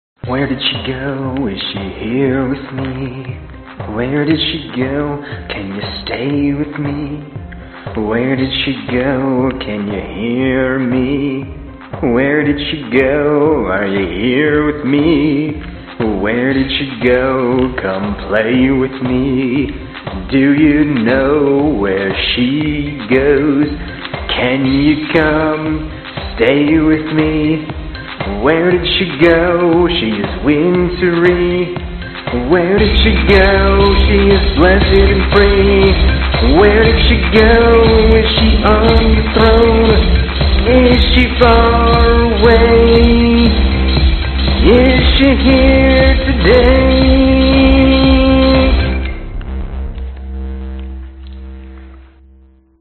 今天早上的第一件事，我在YouTube上看了一部关于经典的卡西欧CZ101合成器的纪录片，我把它用在了贝斯上。
标签： 低音 电子 循环 男声 合成器
声道立体声